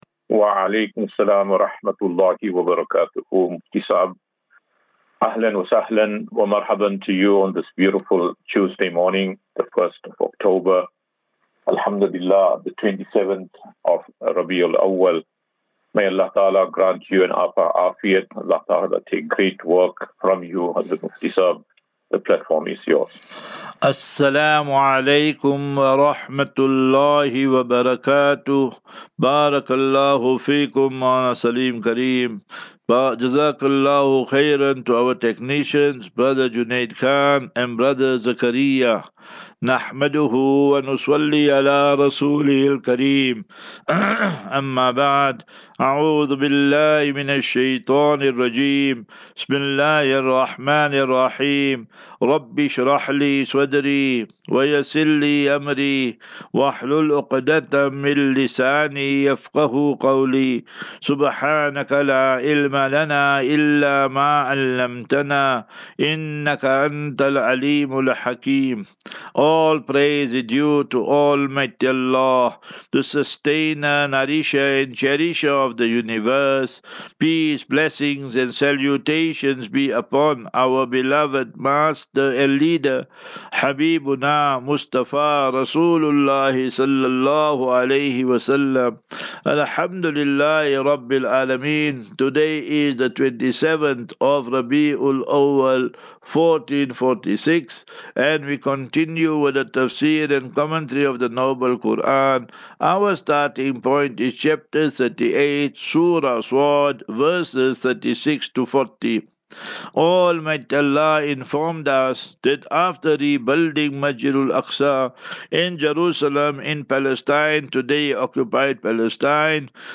Assafinatu - Illal - Jannah. QnA
Daily Naseeha.